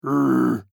AV_bear_short.mp3